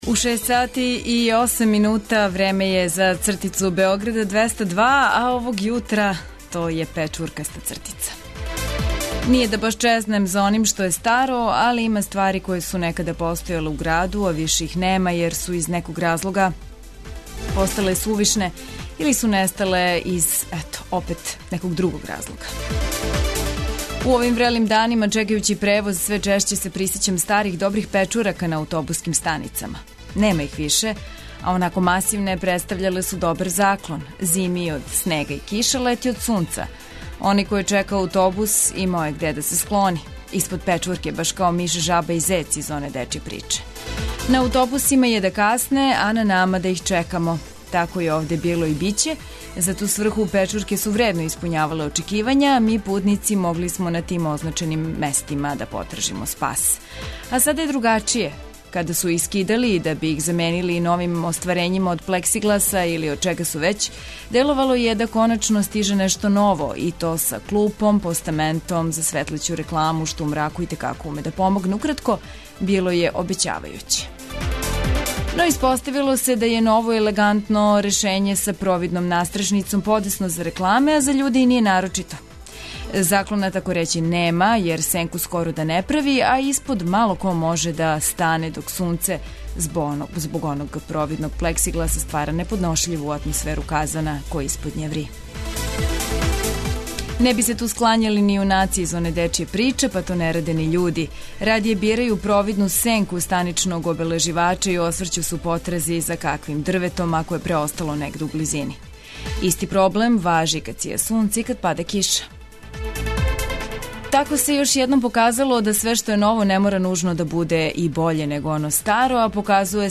Уз причу о ауто-тркама, испијање кафе или чаја, смех у рубрици "Добро јутро", листање штампе, сервисне информације и најновије вести.